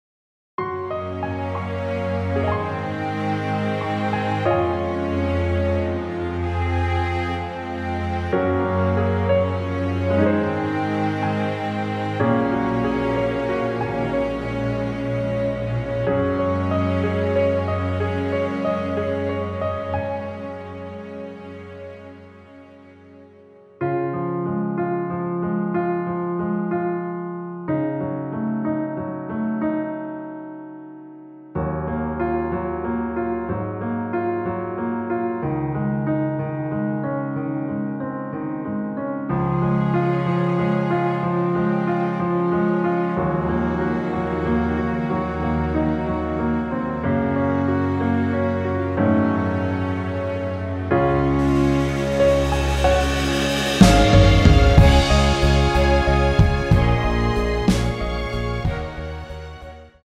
원곡보다 짧은 MR입니다.(아래 재생시간 확인)
Db
앞부분30초, 뒷부분30초씩 편집해서 올려 드리고 있습니다.
중간에 음이 끈어지고 다시 나오는 이유는